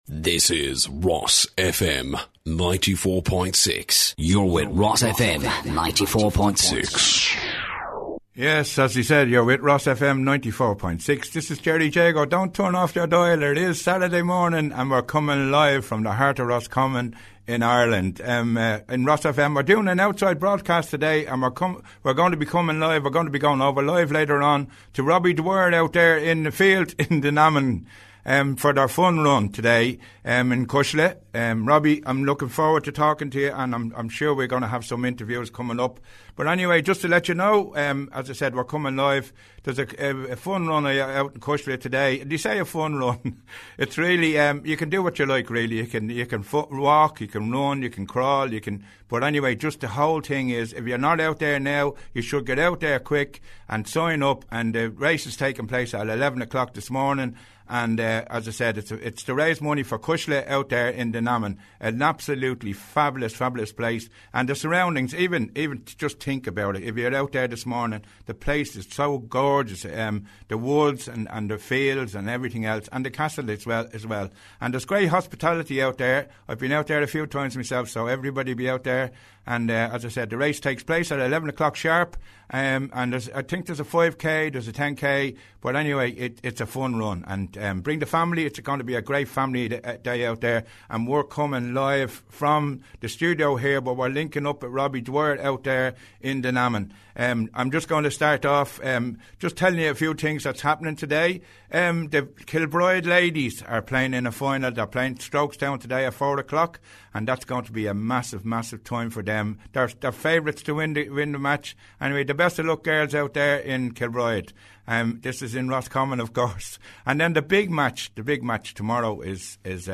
Fun Run For Cuisle Live Special - 16th Sept 2017 - RosFM 94.6
Cuisle_Fun_Run_2017_Part_1.mp3